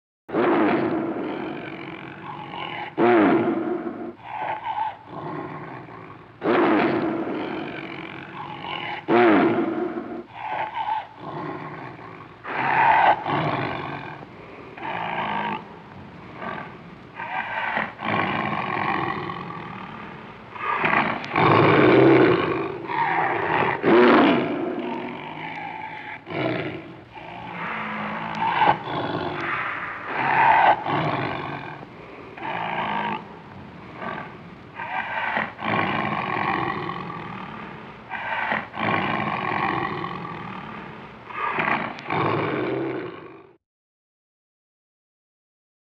tigergrowl.mp3